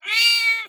SFX_Cat_Meow_01.wav